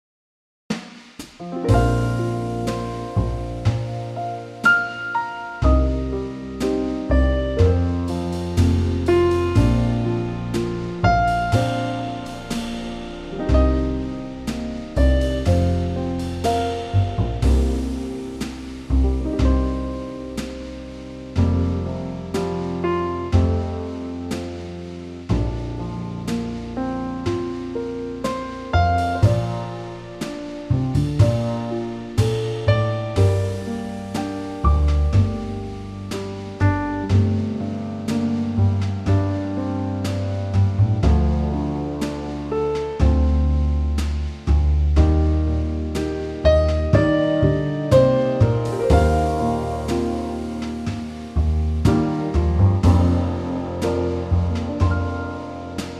Unique Backing Tracks
• Female
key - Bb - vocal range - F to G
Trio arrangement
with a 4 bar intro.